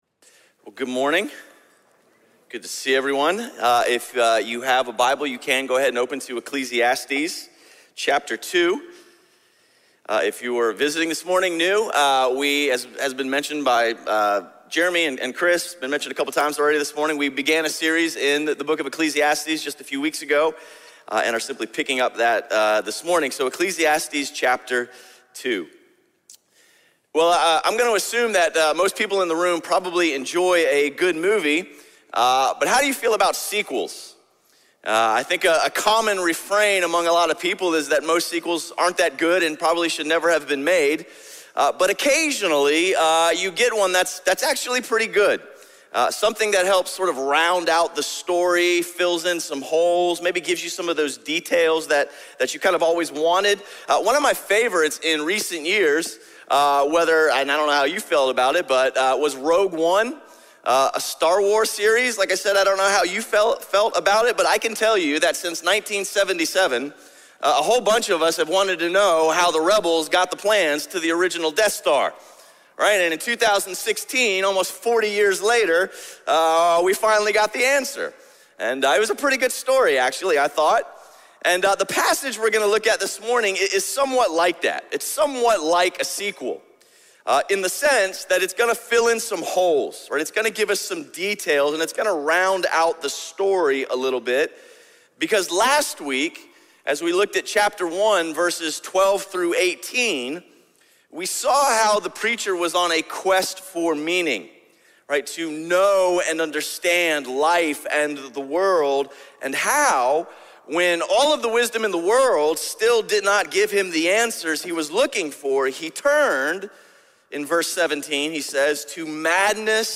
A message from the series "New Life in Jesus."
Sermon series through the book of Ecclesiastes.